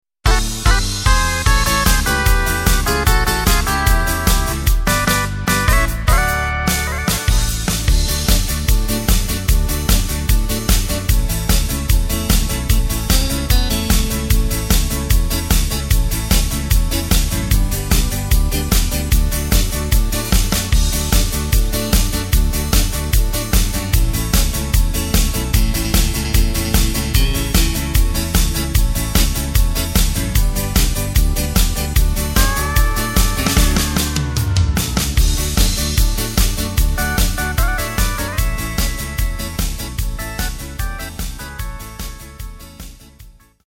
Takt:          4/4
Tempo:         149.50
Tonart:            D
Country-Beat aus dem Jahr 2015 (Neuaufnahme)!
Playback mp3 Demo